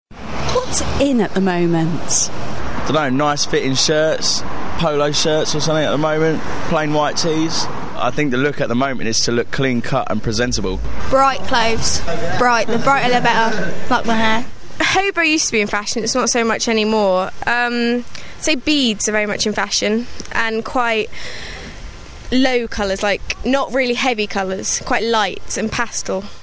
Hear what people in London say, then join the conversation!